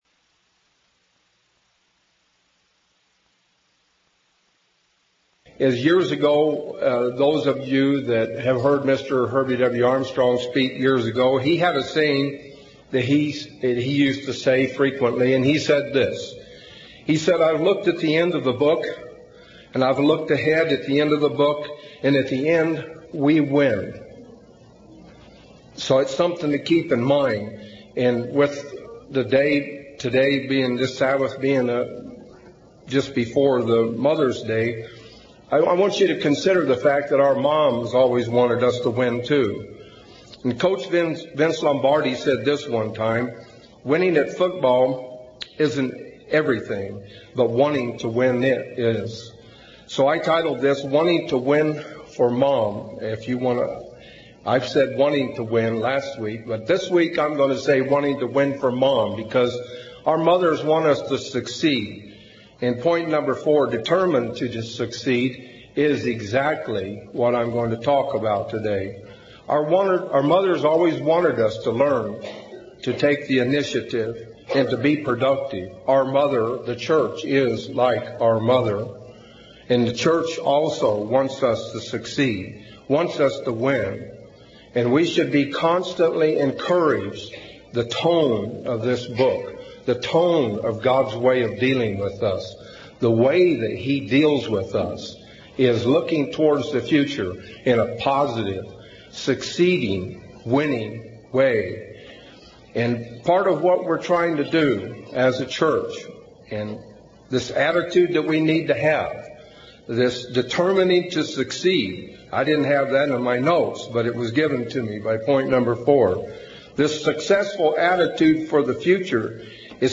UCG Sermon Honor Your Father and Mother mother's day mothers Studying the bible?